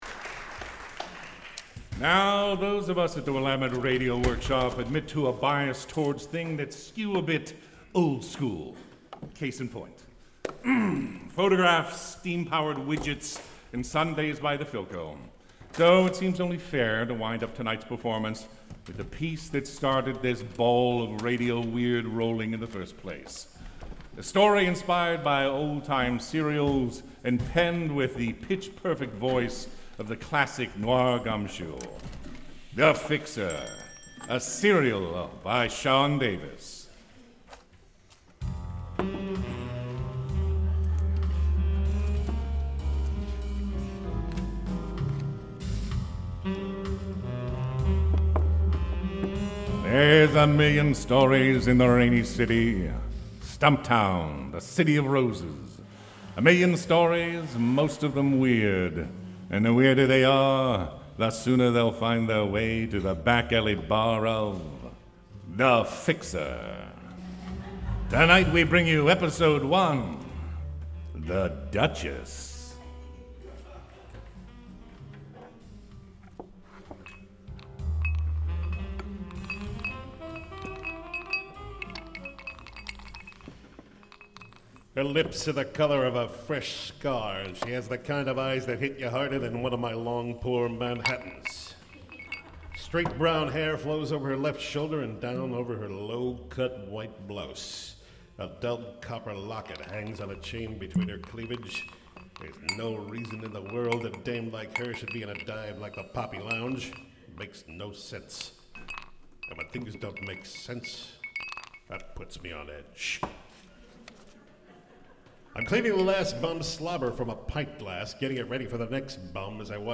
One of many stories from the Powells Best Seller City of Weird by Forest Avenue Press, performed by the Willamette Radio Workshop.